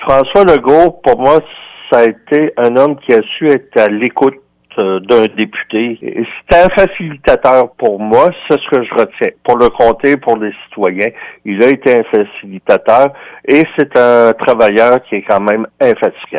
Rejoint par notre service de nouvelles, Denis Tardif ne s’est pas fait prier pour faire l’éloge de celui qui va occuper les fonctions de premier ministre du Québec pour encore quelques semaines avant de tourner la page.